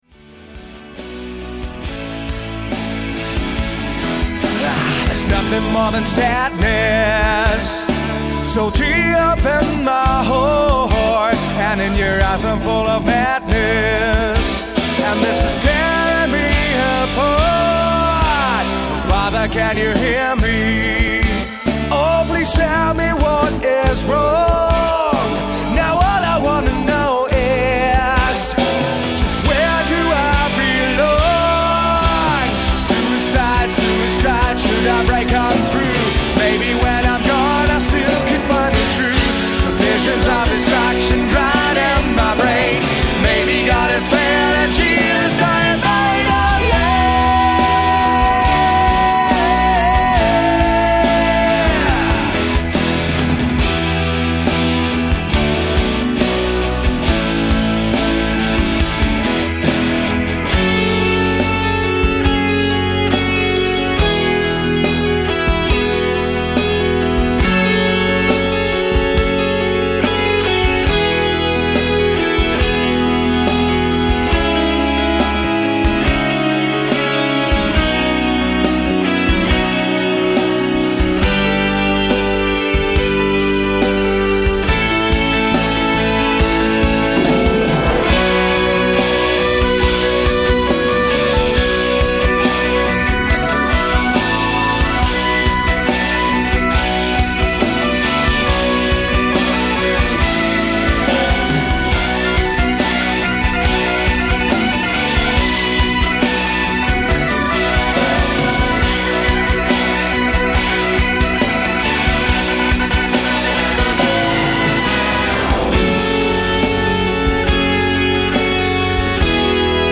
electric & acoustic guitars, vocals
bass, vocals, horns
keyb., organ, moog, vocals
drums, perc